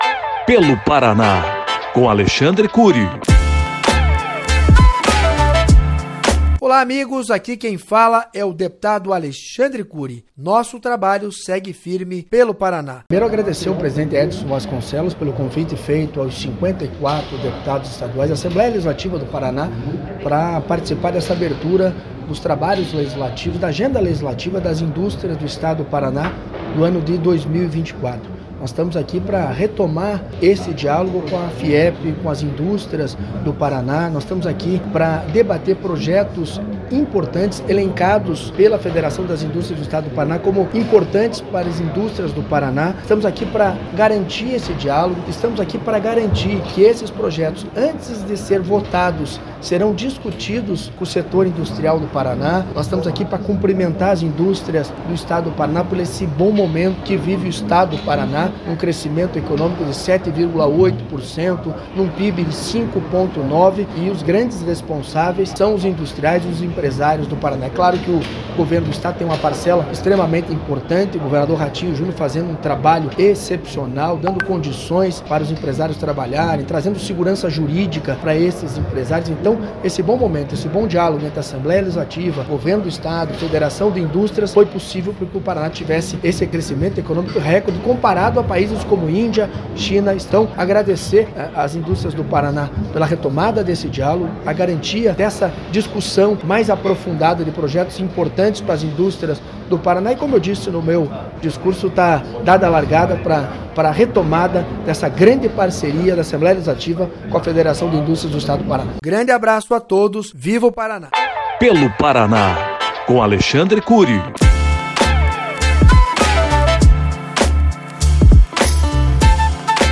Durante a cerimônia de abertura dos trabalhos legislativos de 2024, o deputado Alexandre Curi enfatizou a importância da continuidade do diálogo entre a Assembleia Legislativa do Paraná (ALEP) e a Federação das Indústrias do Estado do Paraná (FIEP).